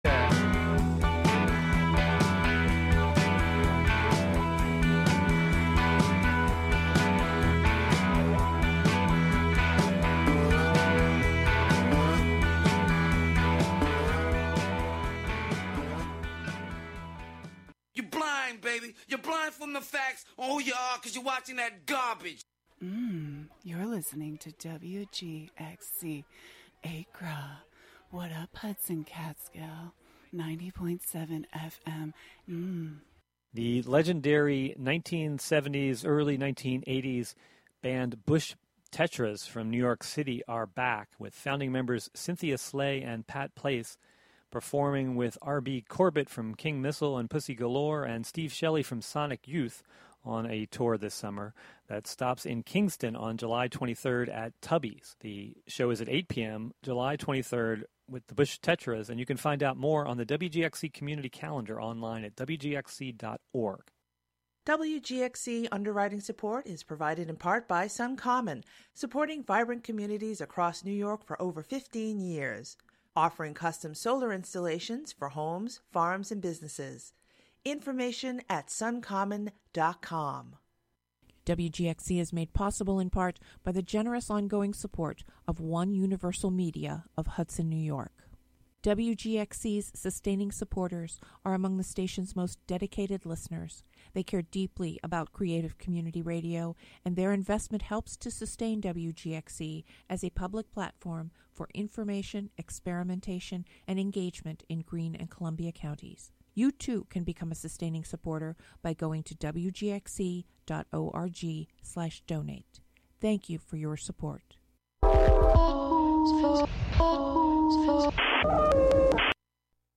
This broadcast features music inspired by the ancient forests of the Catskills.
The show features music, field recordings, performances, and interviews, primarily with people in and around the Catskill Mountains of New York live from WGXC's Acra studio.